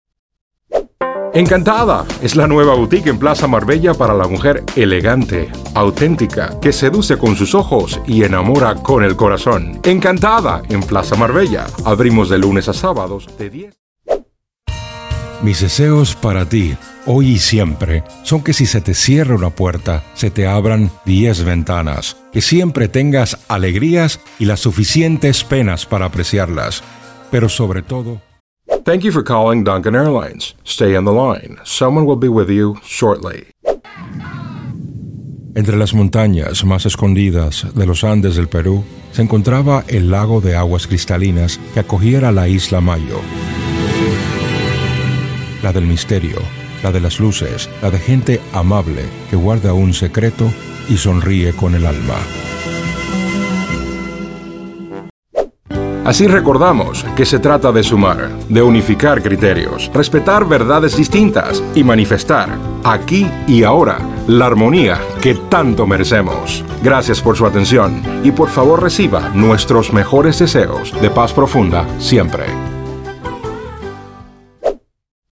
Voces Masculinas| Ultravoces Locutores, Actores de Voz y Doblaje, Panamá | Ultravoces Locutores, Actores de Voz y Doblaje, Panamá
Perfil: Una voz clara, grave, confiable, y con excelente dicción.
DEMO INTRO :